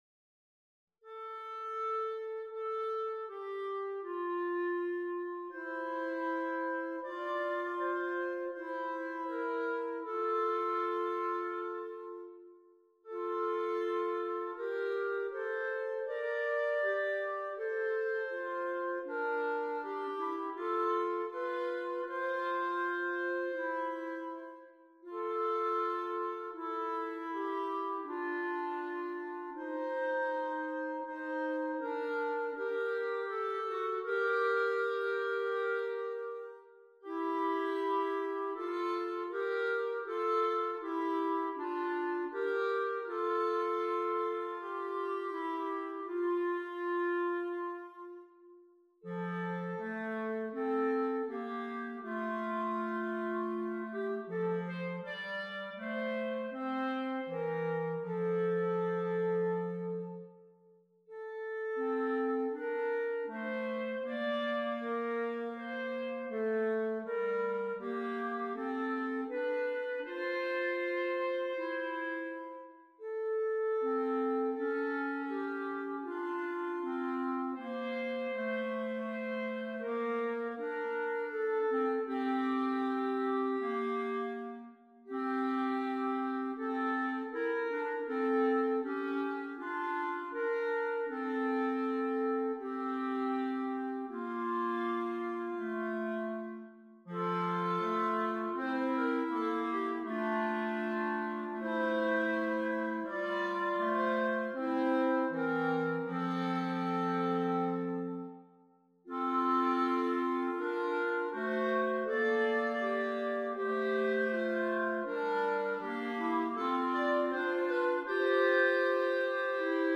for clarinet trio